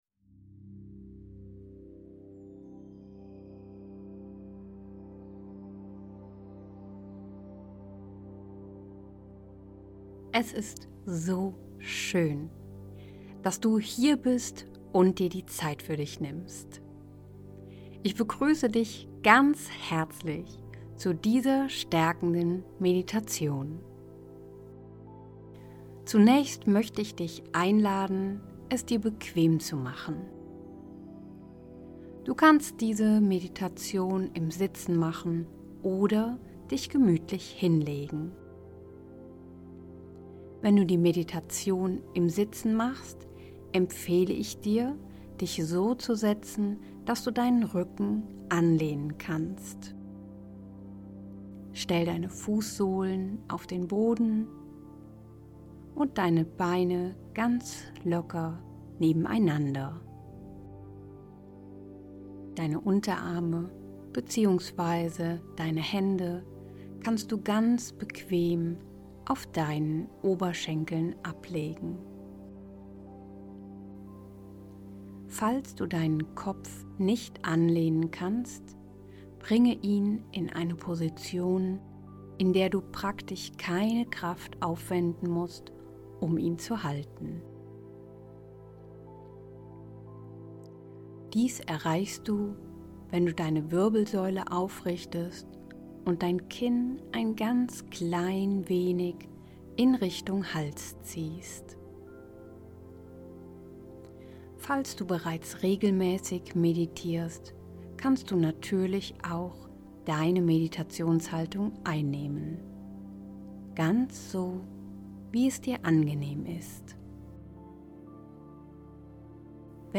Gefuehrte-Meditation-Selbstwert.mp3